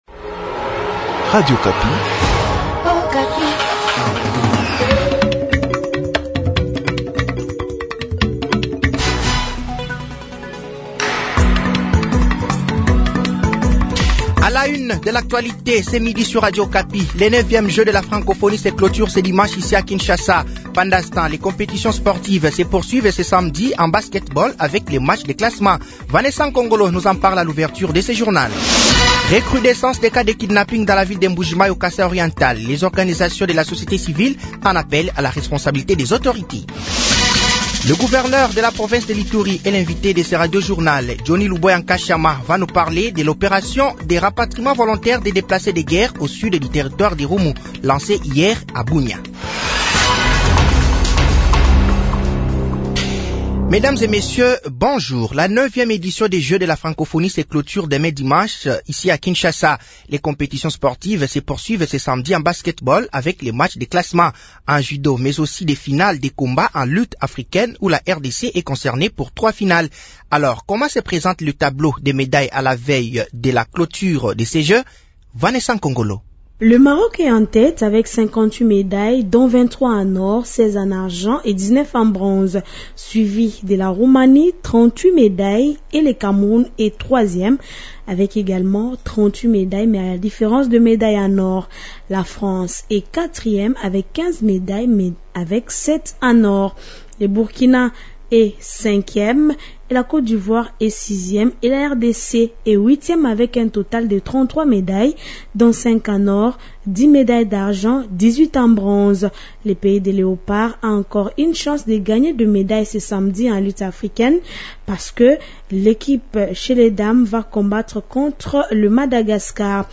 Journal midi
Journal français de 12h de ce samedi 05 août 2023